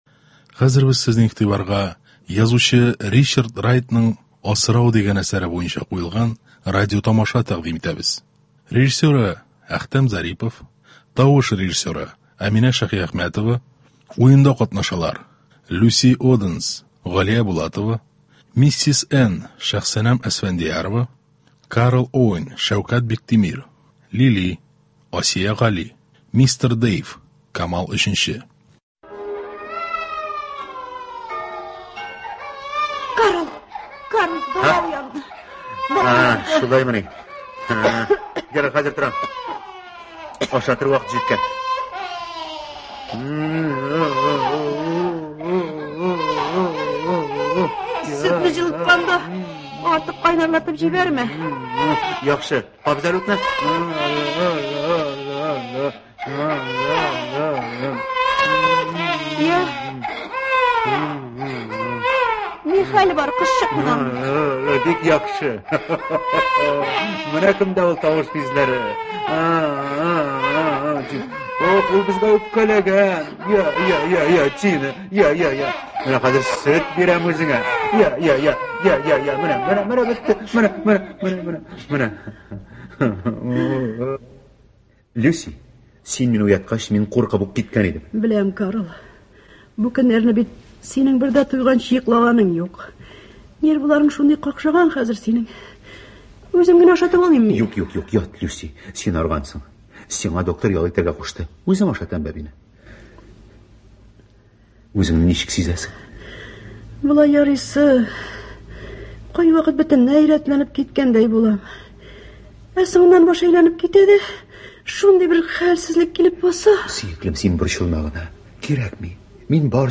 Моноспектакль.